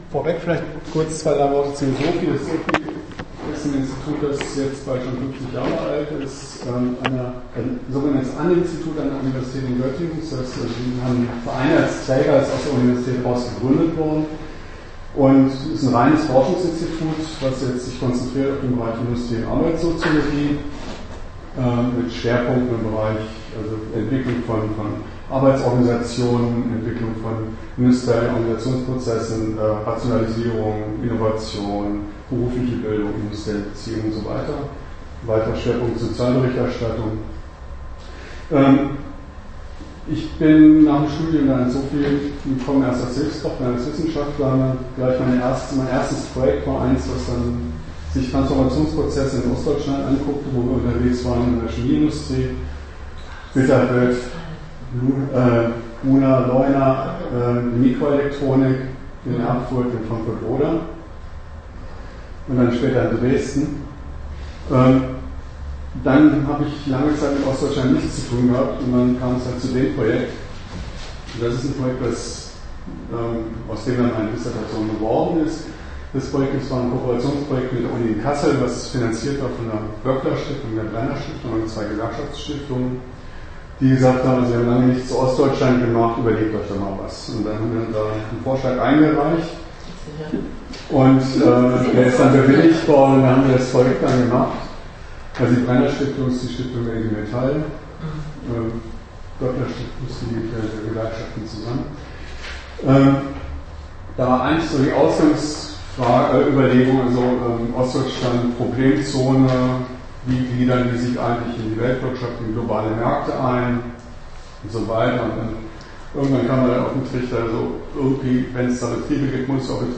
Buchvorstellung